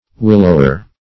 willower - definition of willower - synonyms, pronunciation, spelling from Free Dictionary Search Result for " willower" : The Collaborative International Dictionary of English v.0.48: Willower \Wil"low*er\, n. A willow.